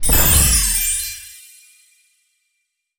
healing_magic_spell_02.wav